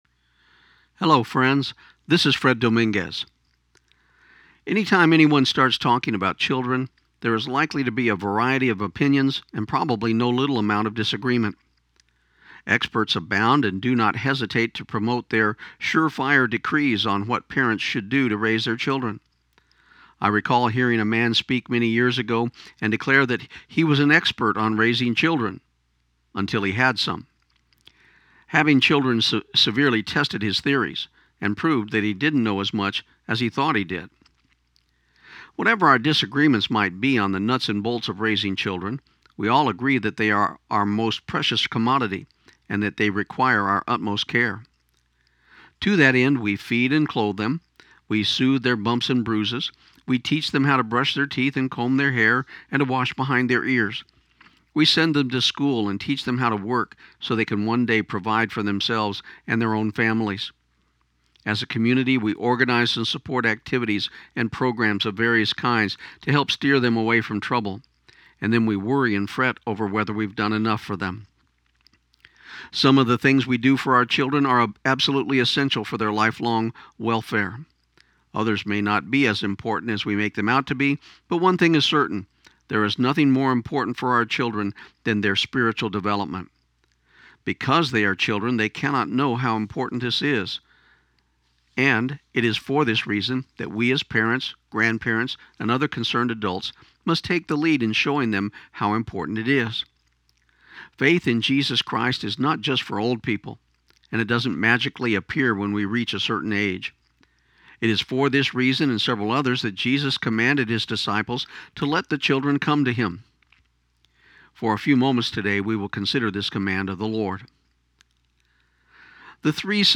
This program aired on KIUN 1400 AM in Pecos, TX on December 11, 2015.